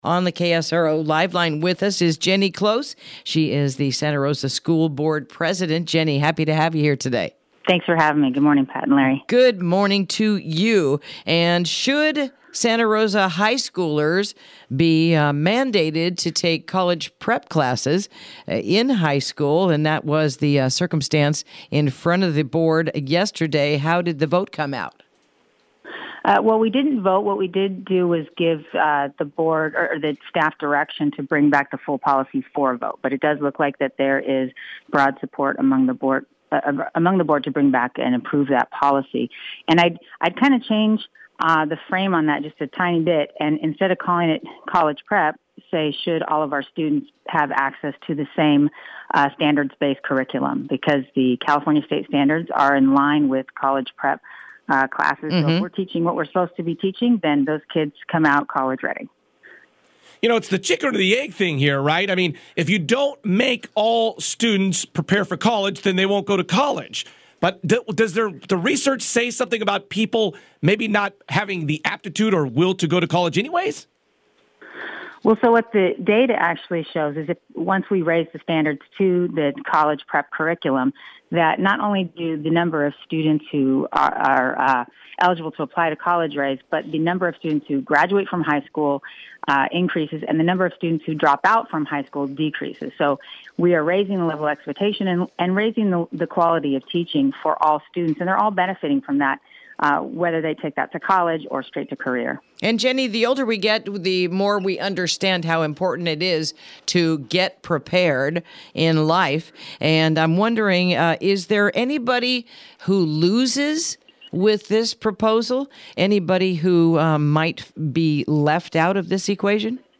Interview: Should Santa Rosa High School Students Be Mandated to Take College Prep Classes?